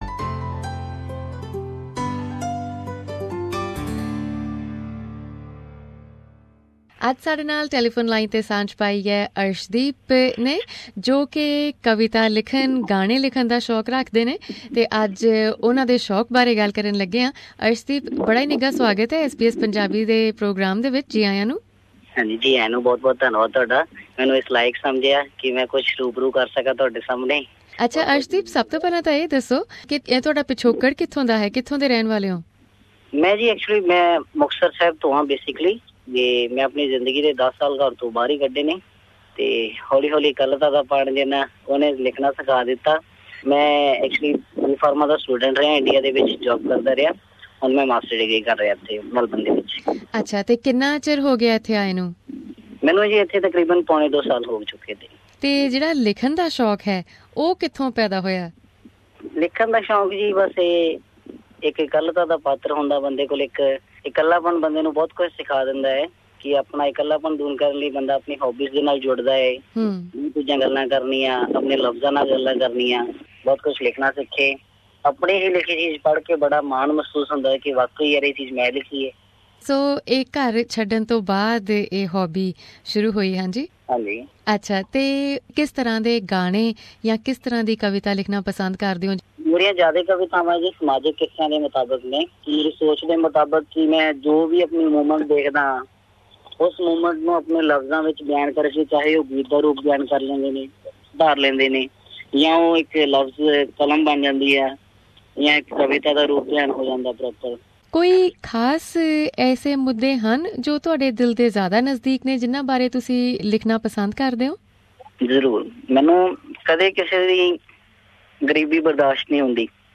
Budding Punjabi Poet with a soulful voice
In this interview